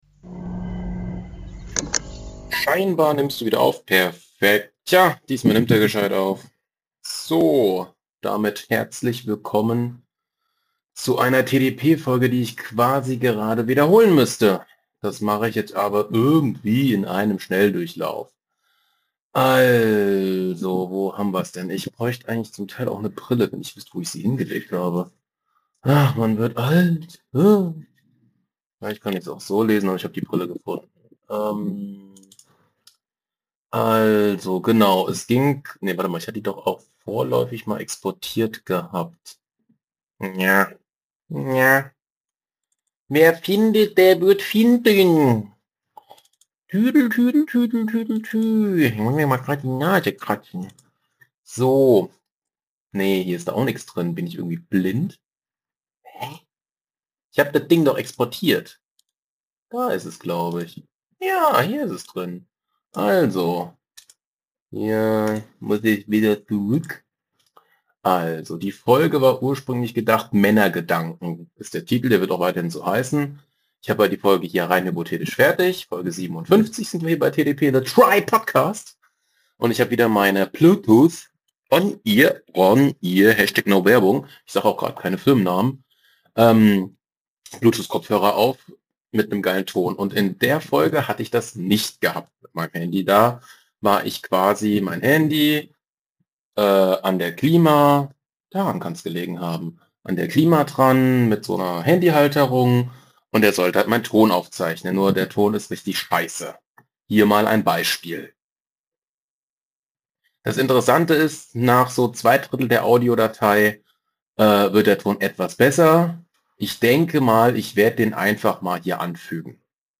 Moin, in dieser Podcast Ausgabe von TDP, rede über Männer Gedanken. Das ganze war beim Autofahren gewesen. Leider war die Klimaanlage zu laut gewesen, dadurch kann ich von der Ursprungsdatei nur das Ende verwenden.